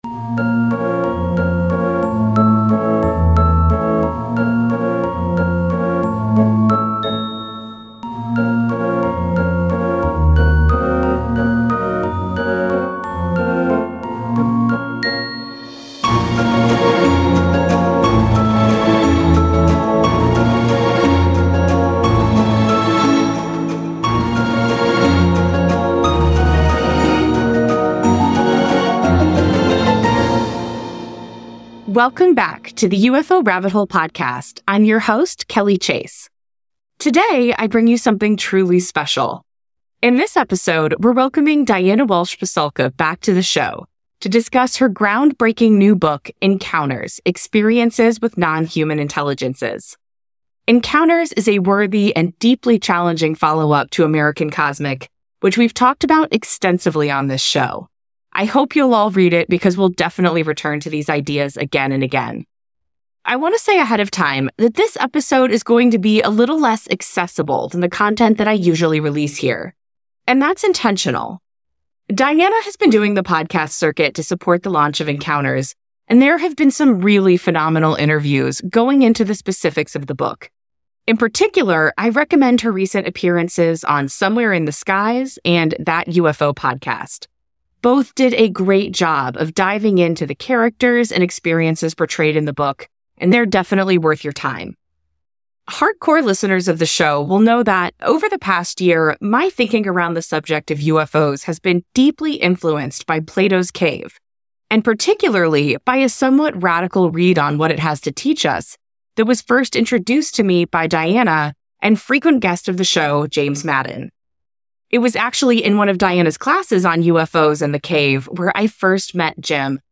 Social Sciences, Society & Culture, Science, Documentary